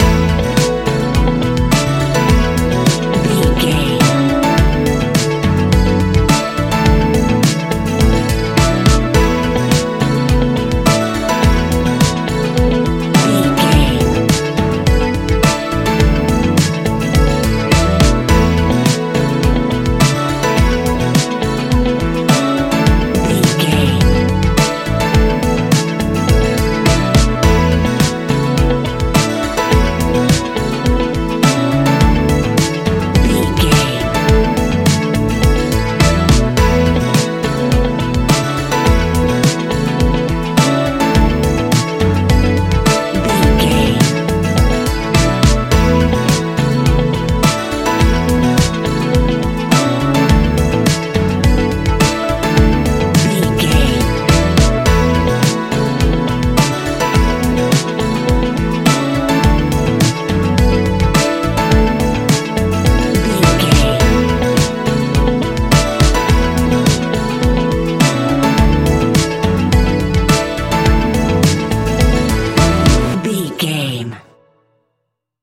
Ionian/Major
ambient
electronic
new age
downtempo
pads